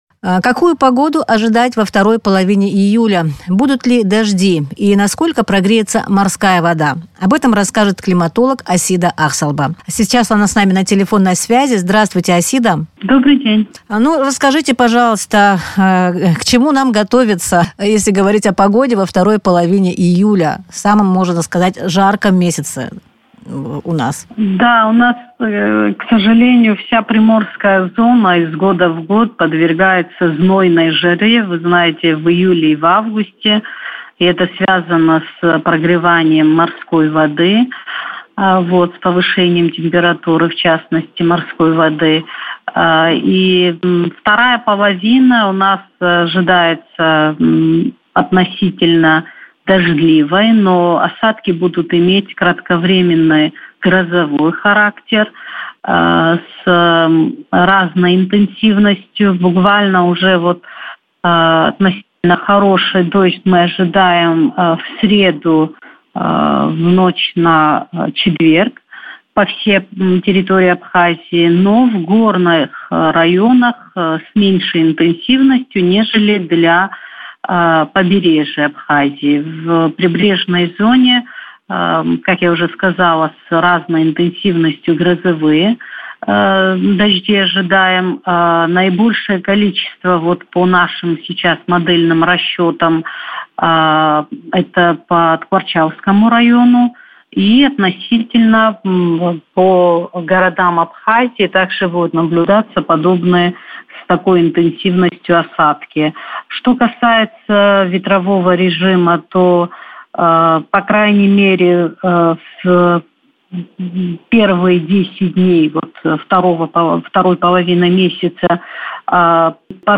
в интервью радио Sputnik